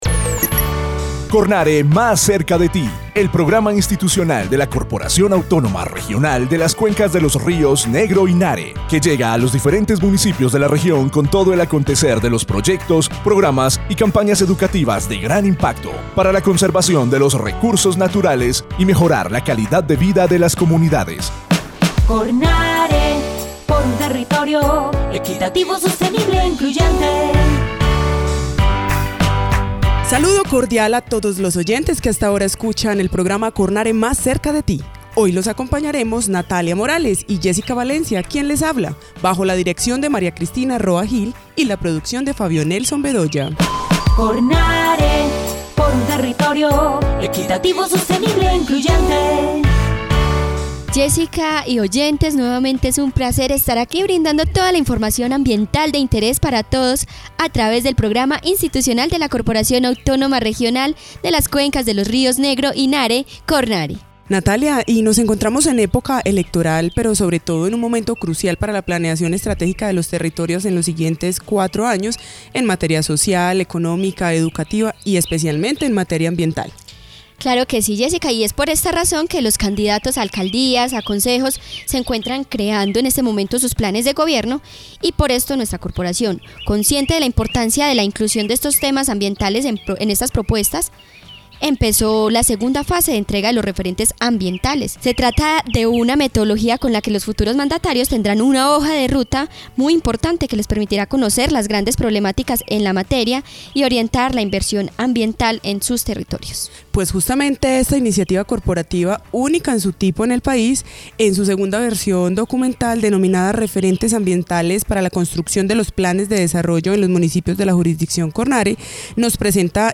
Programa de radio